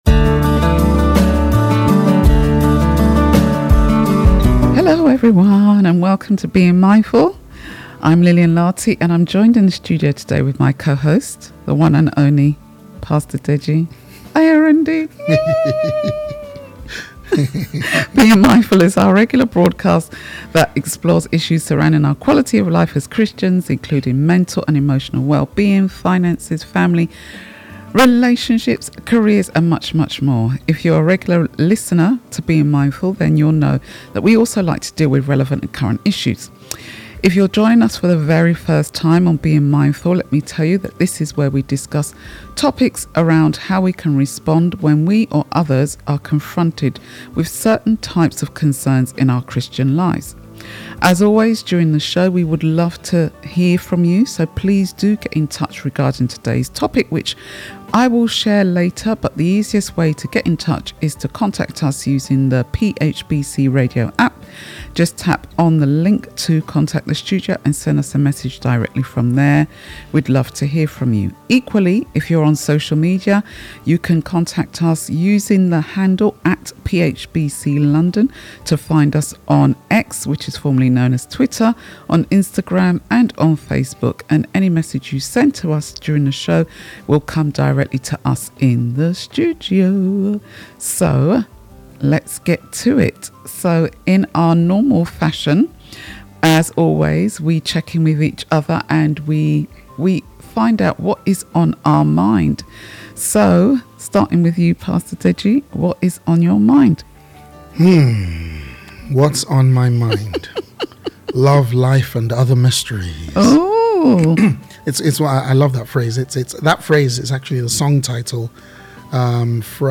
Click to listen or download a recording of this live show.